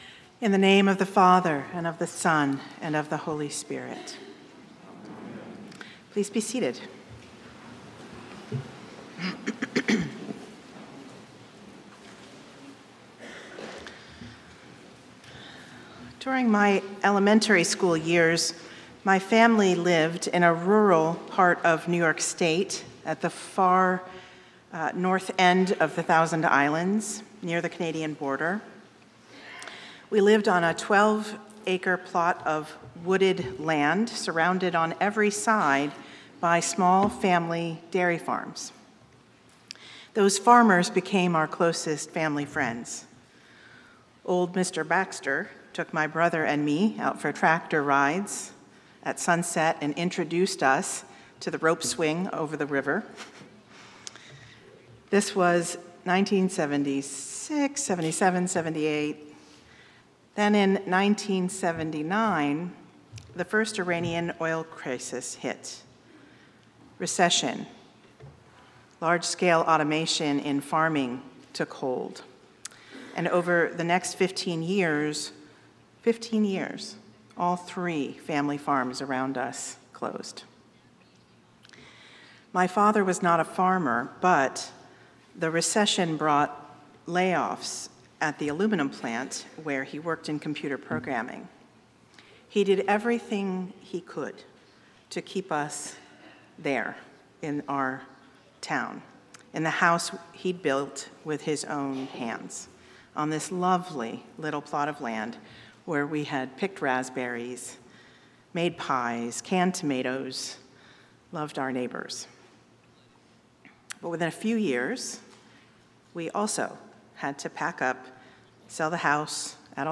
St-Pauls-HEII-9a-Homily-05APR26.mp3